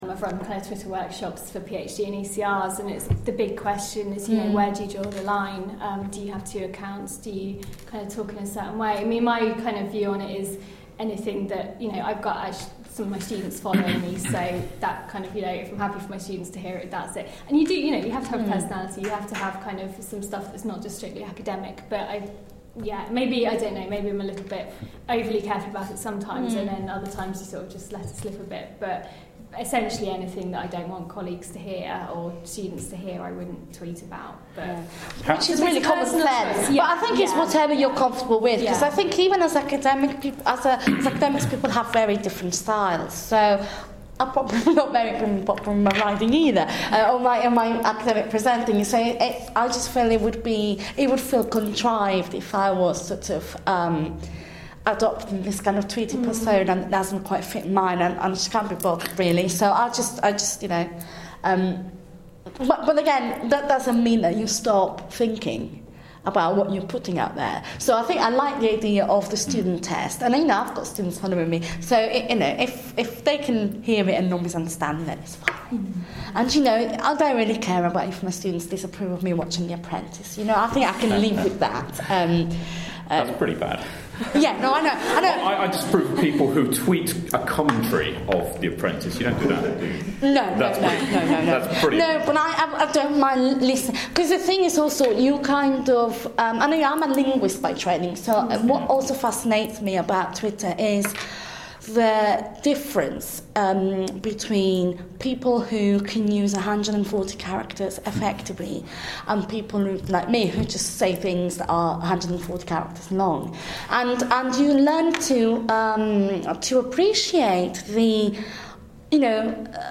The panel (below) responds at this Digital Change GPP event earlier in the year.